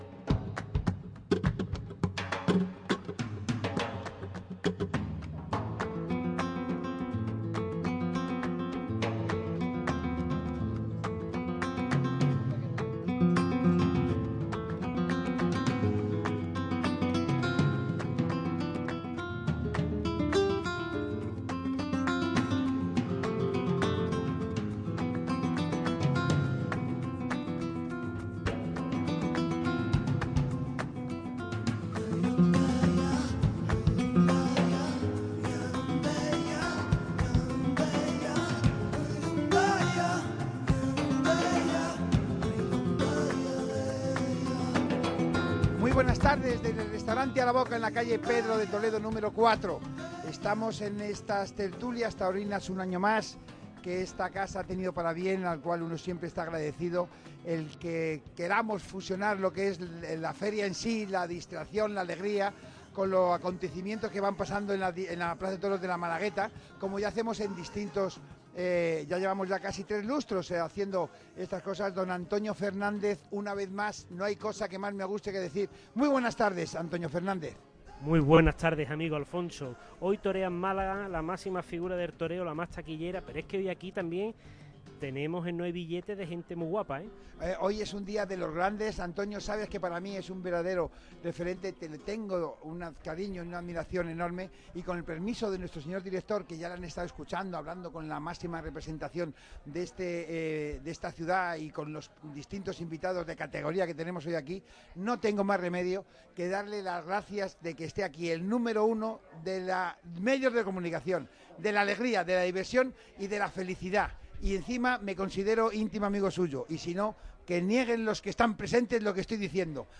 Faena magistral en Araboka con la habitual tertulia taurina de Radio MARCA Málaga - Radio Marca Málaga
A lo largo de la hora de tertulia, por el micrófono rojo pasó una gran cantidad de invitados y figuras del toreo.